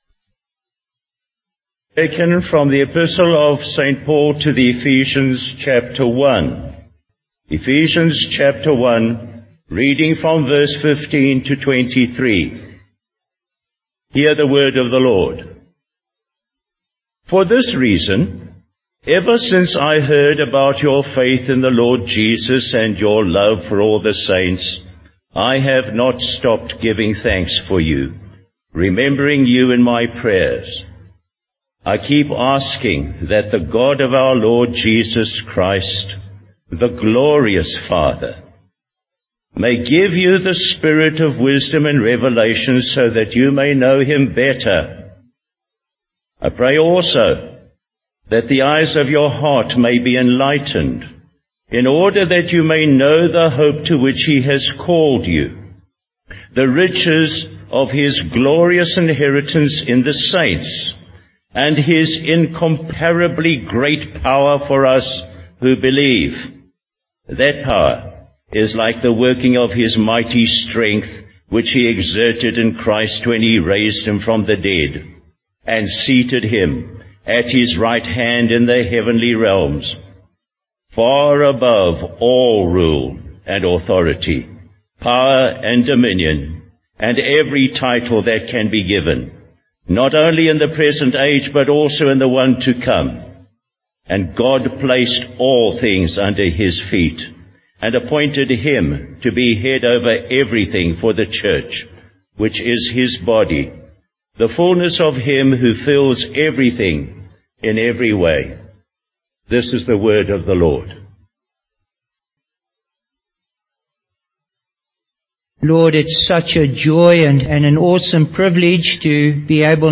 Bible Text: Ephesians 1:15-23, Psalm 1:1-6 | Preacher: Bishop Warwick Cole-Edwards | Series: Ephesians